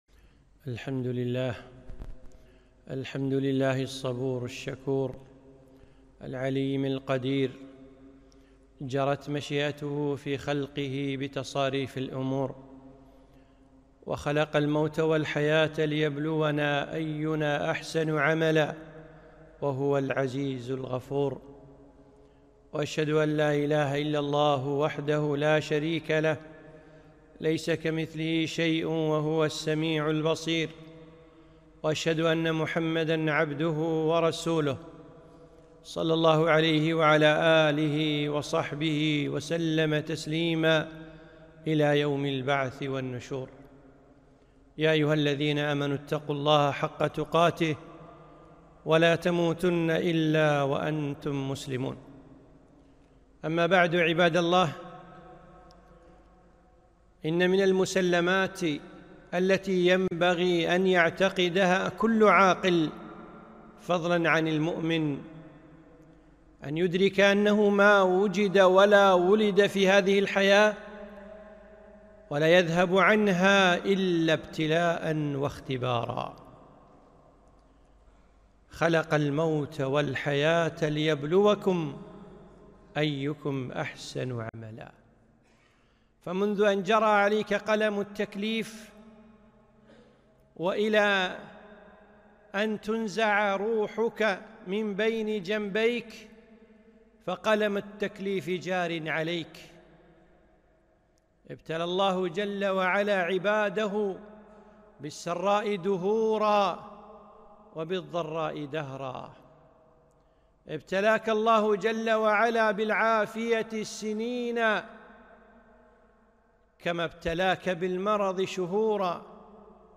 خطبة - ( ولا تقتلوا أنفسكم )